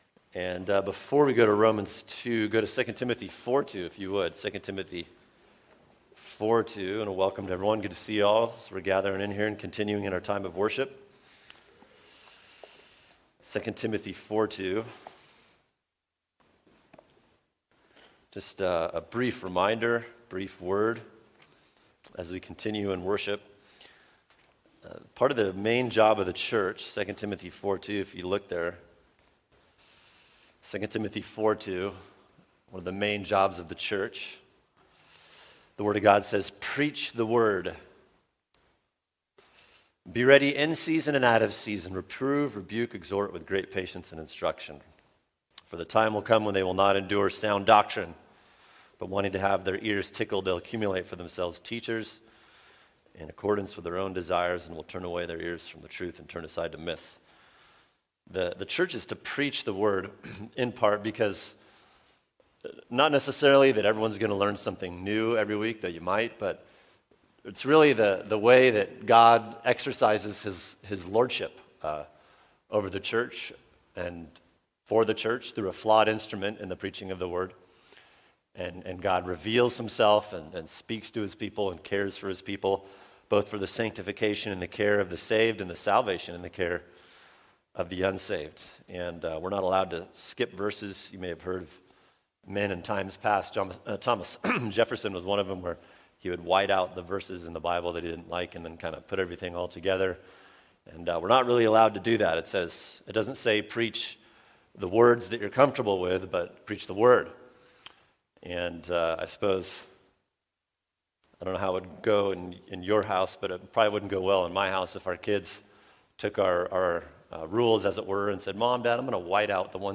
[sermon] Romans 2:7-10 Evidence & Outcome of a Living Faith | Cornerstone Church - Jackson Hole